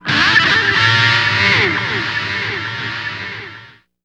Index of /90_sSampleCDs/Zero-G - Total Drum Bass/Instruments - 2/track43 (Guitars)
04 Wembley G.wav